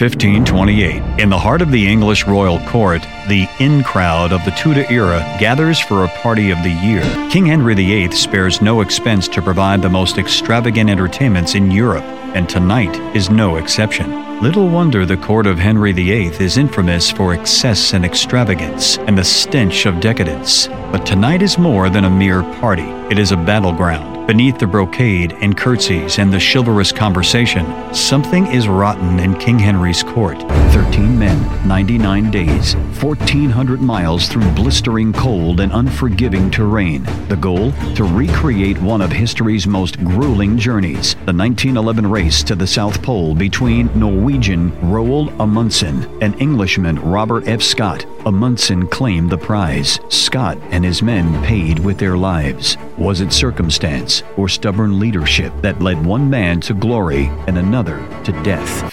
Narration VO Demo